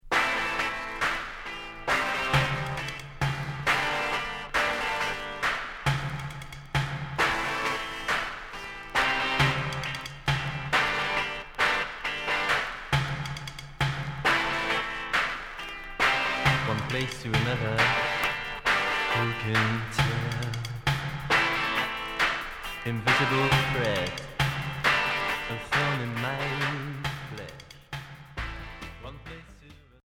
Cold wave expérimentale Unique 45t retour à l'accueil